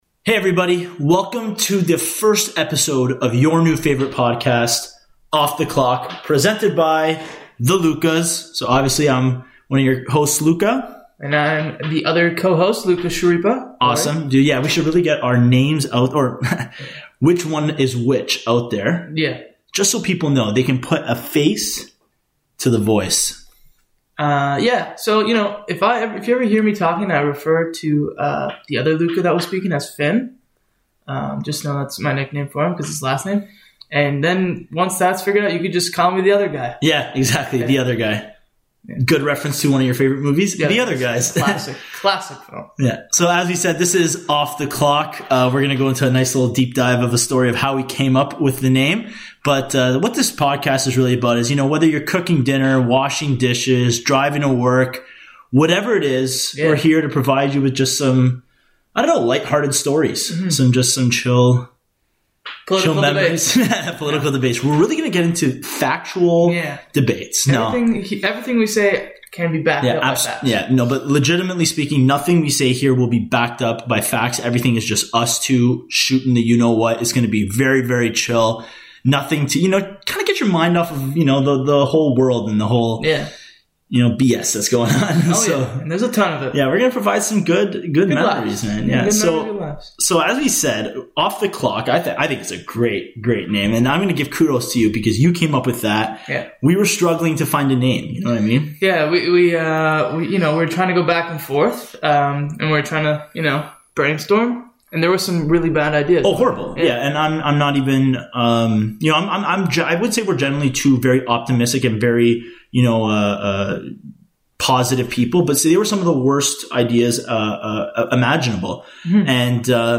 In the time we’re on air, we aim to provide you with an hours worth of funny, light conversation on a variety of topics each episode. This week, on our first episode, we talk about controversial pie rankings, Thanksgiving, and the irrelevance of carrots as a side dish. We then segway into reminiscing our elementary school days, and the fight that existed around claiming bag fries in the school yard.